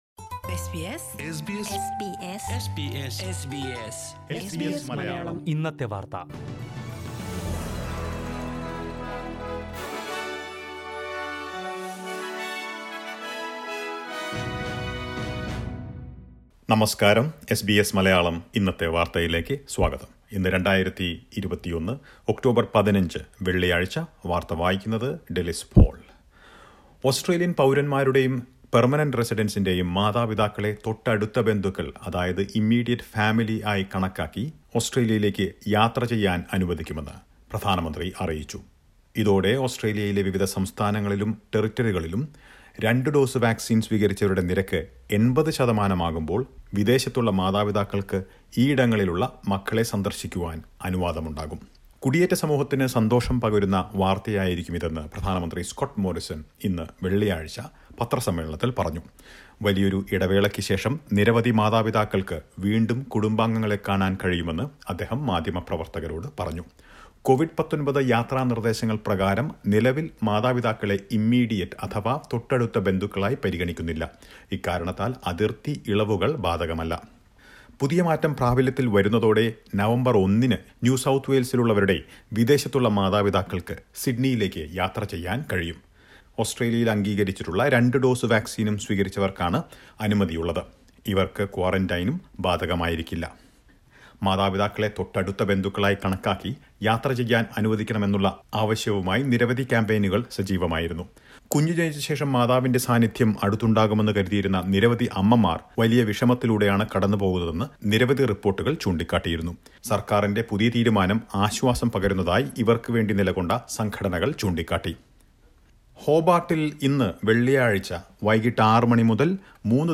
news_1510.mp3